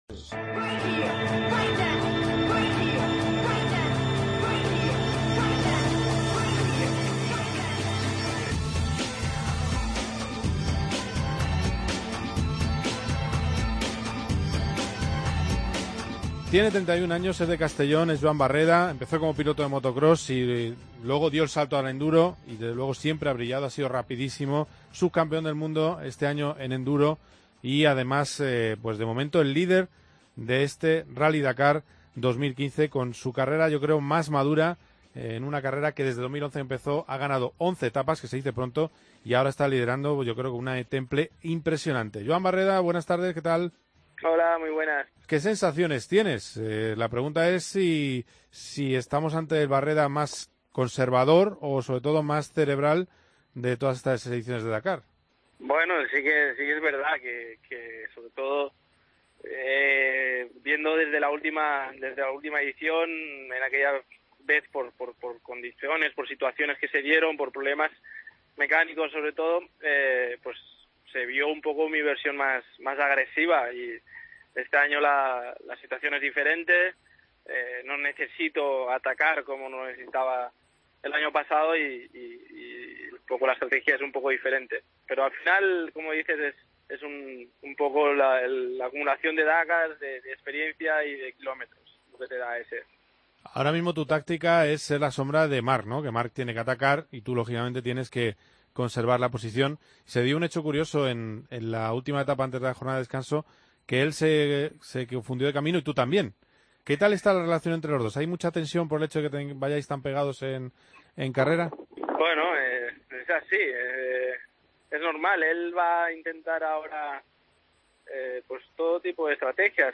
AUDIO: Entrevistamos en COPE GP a los dos aspirantes al triunfo en el Rally Dakar 2015 en la categoría de motos.